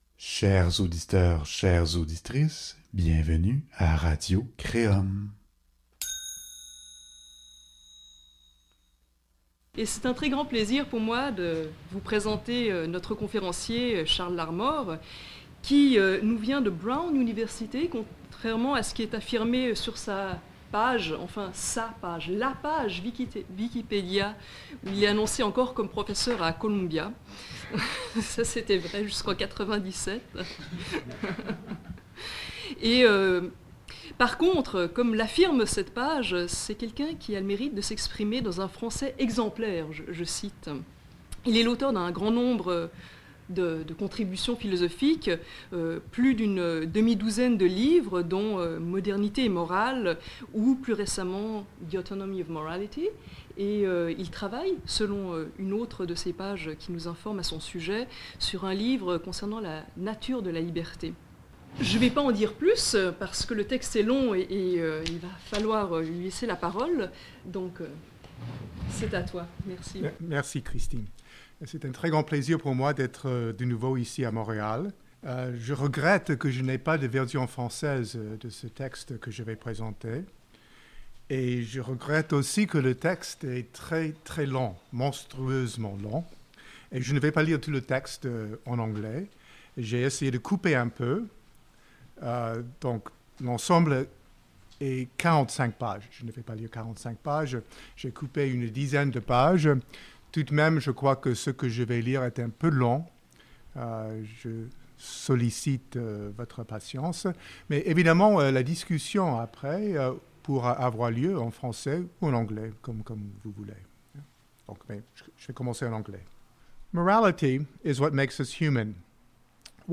Vous pouvez maintenant écouter sur Radio-Créum la conférence que Charles Larmore a présenté au ce lien pour un résumé de la présentation).